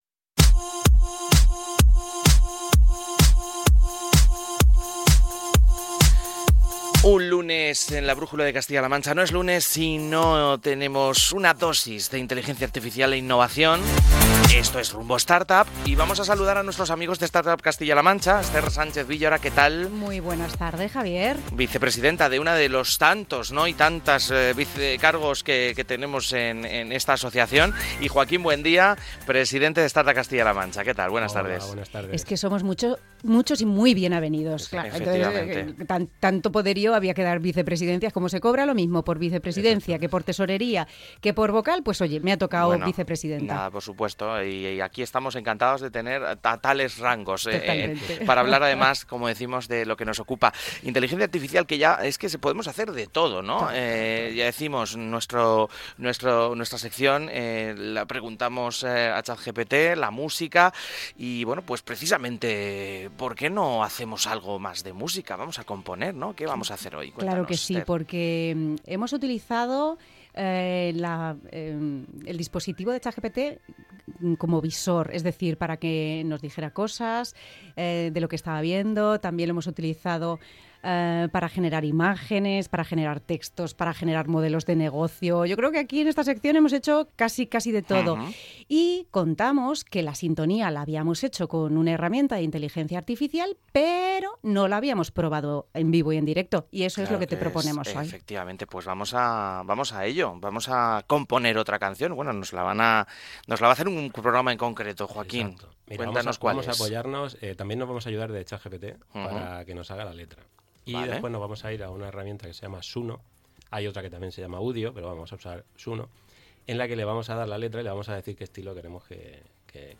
En el programa hubo risas, sorpresas y hasta pequeñas “alucinaciones” de la IA: ¿miel en el pelo?